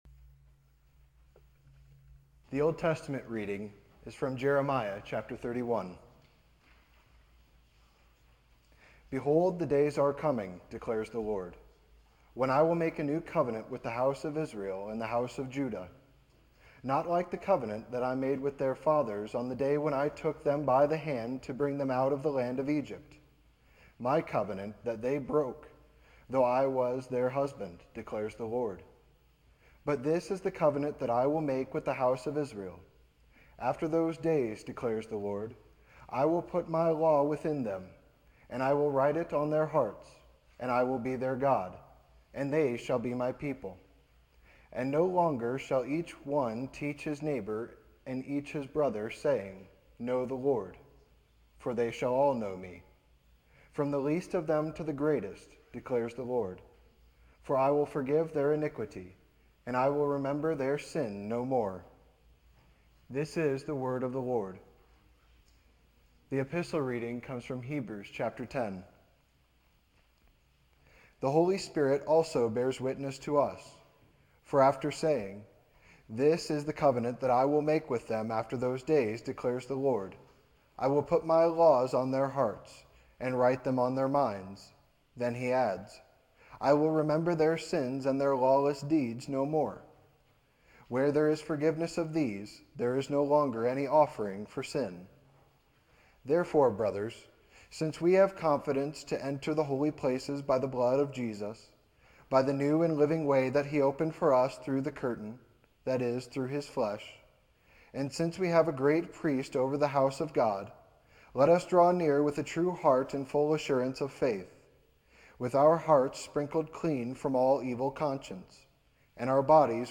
This sermon emphasizes the importance of embraci…